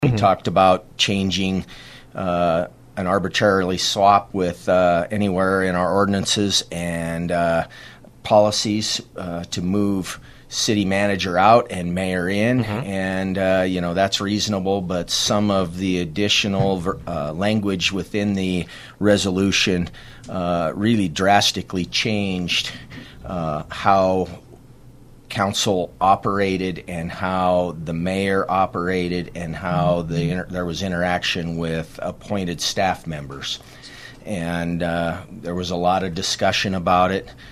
City Government transition resolution point of contention at Sturgis Council Meeting.